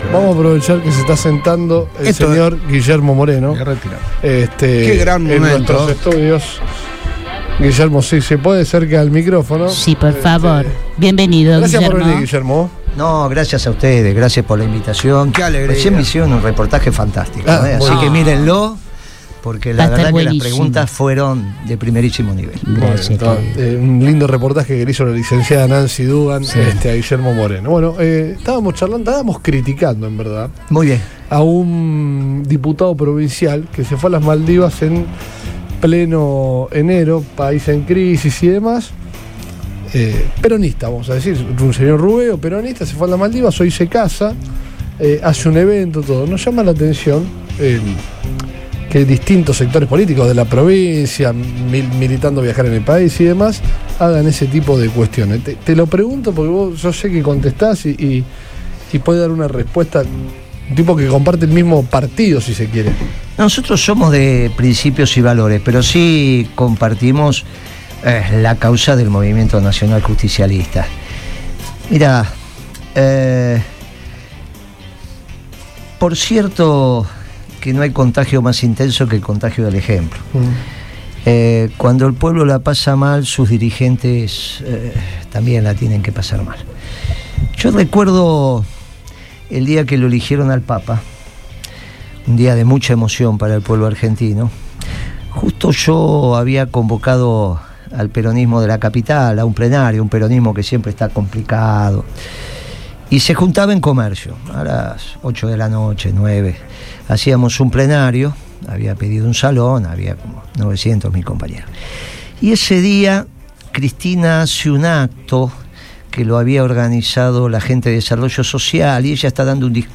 En el marco de su paso por Rosario, Guillermo Moreno visitó los estudios de Radio Boing.